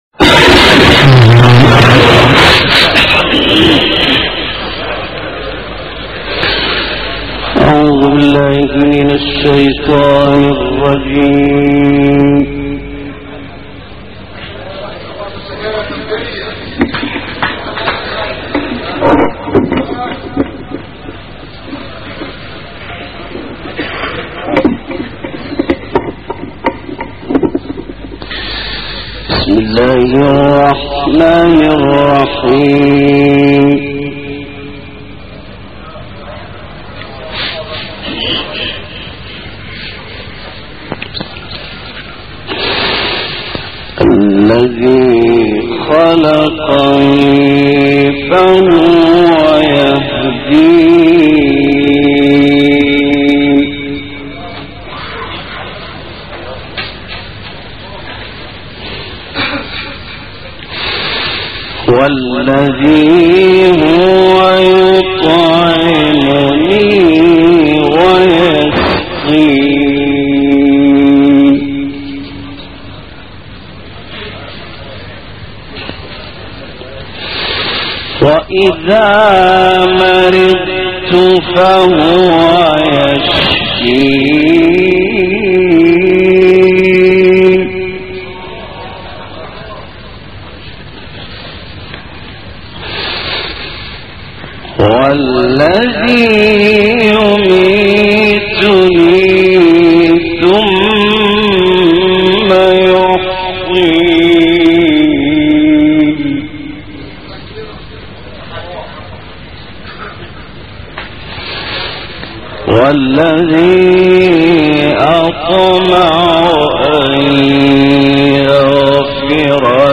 تلاوت محمد اللیثی از سوره شعراء به مناسبت سالروز درگذشت
به گزارش خبرگزاری بین المللی قرآن(ایکنا) تلاوت آیات 78 تا 89 سوره شعراء و آیات 1 تا 9 سوره مبارکه تکویر با صوت محمد اللیثی، قاری برجسته مصری به مناسبت سالروز درگذشت این قاری قرآن کریم در کانال تلگرامی گروه استاد اللیثی منتشر شده است.